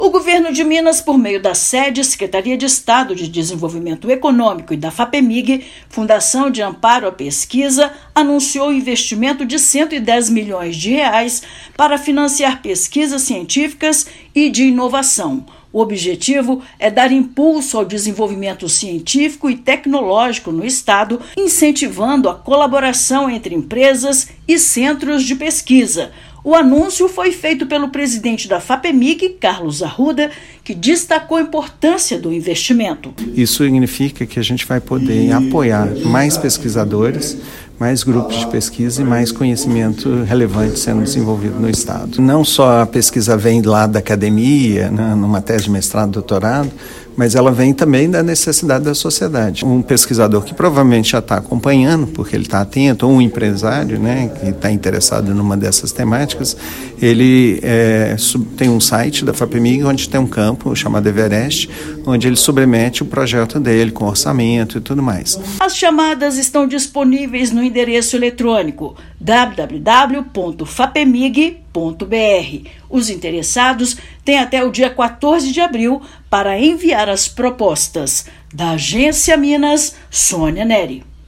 Valor irá beneficiar instituições estaduais e projetos em parceria com empresas. Ouça matéria de rádio.